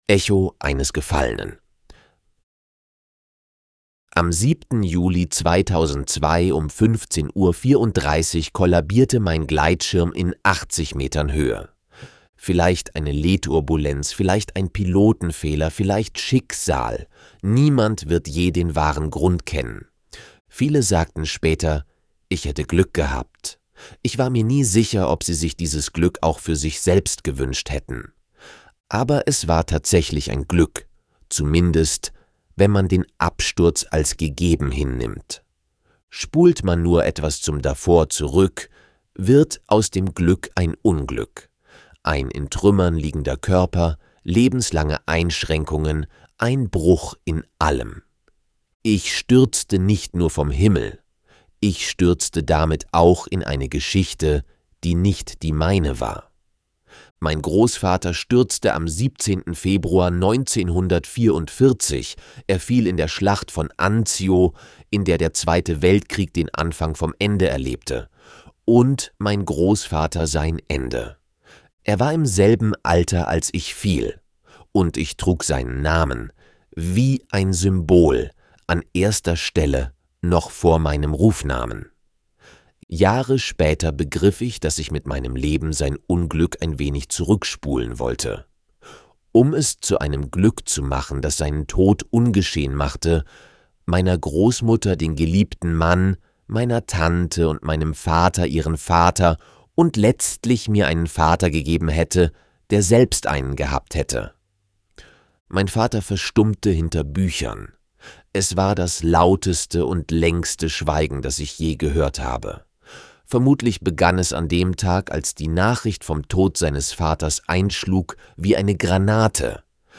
Hörbücher der Trilogie
Trilogie als Hörbuch, erhältlich als einzelne Hörbücher: